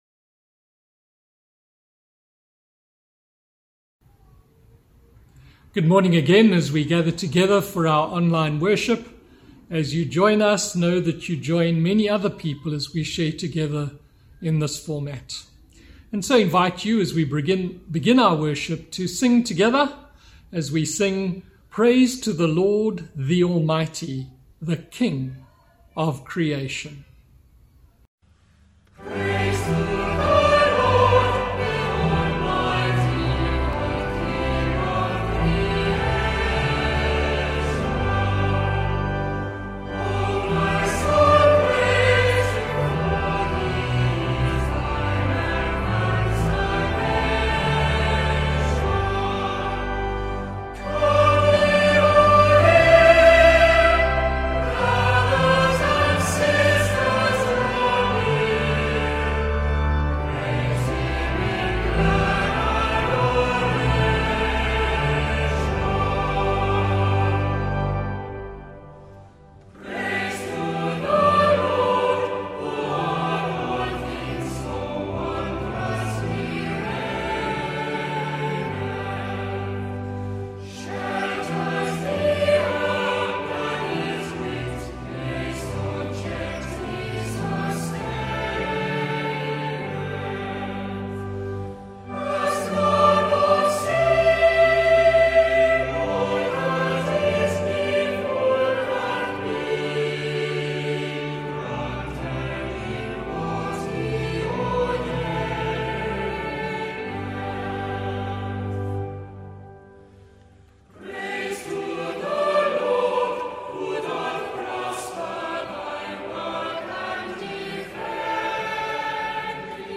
Sermon – 5th May